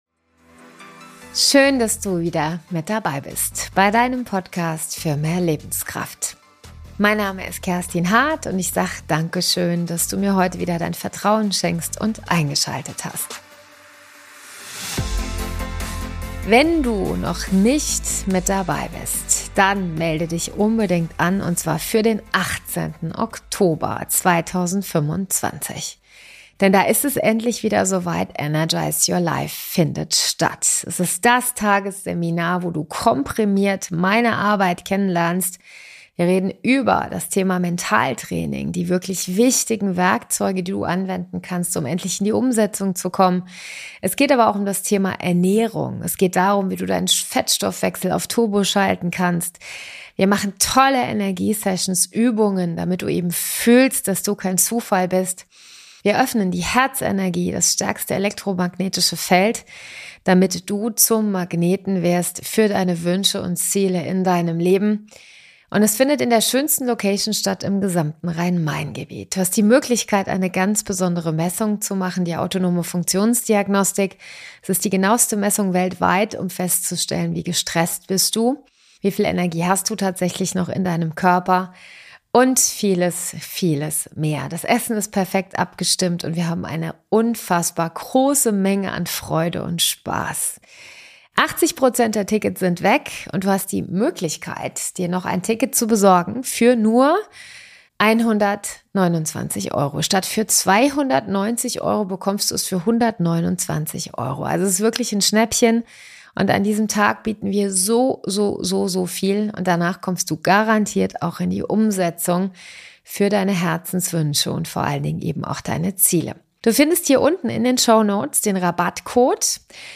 In diesem sehr spannenden Interview nimmt er uns mit auf eine sehr persönliche Reise. Er berichtet sehr offen und ehrlich über seinen Wake up call in 2015 in Form eines Schlaganfalls.